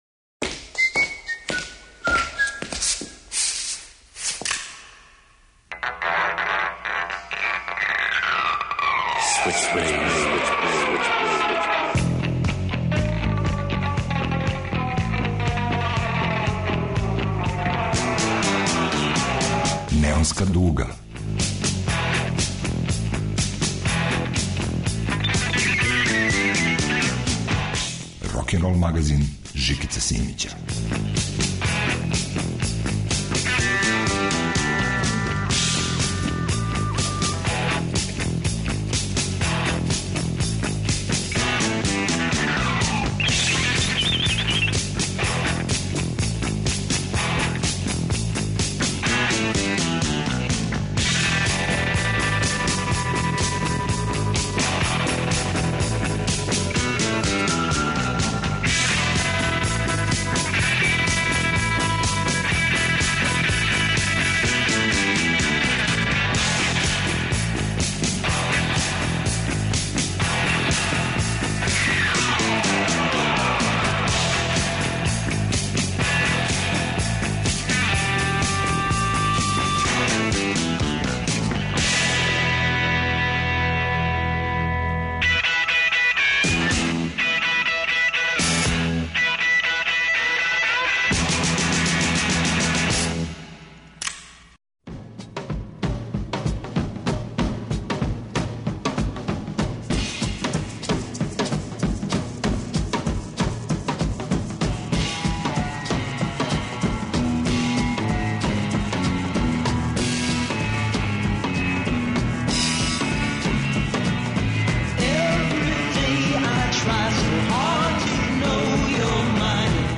Рокенрол као музички скор за живот на дивљој страни. Вратоломни сурф кроз време и жанрове.